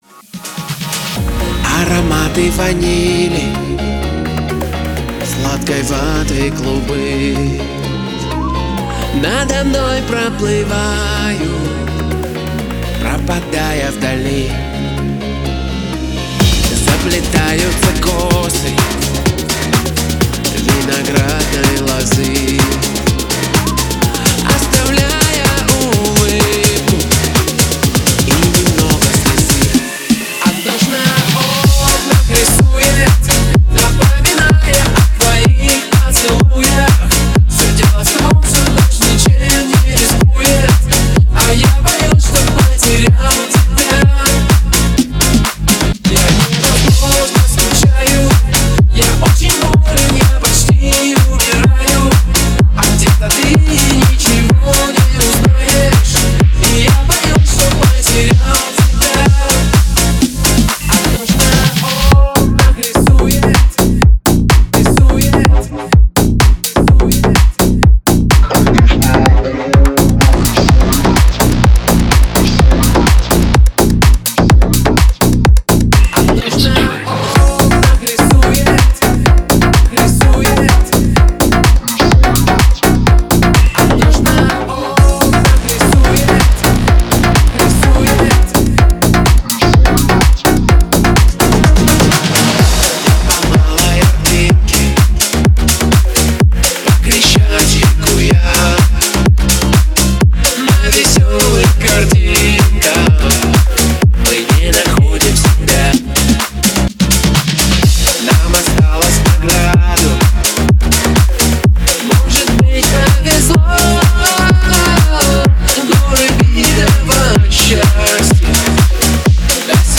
Жанр: Pop, Dance, Other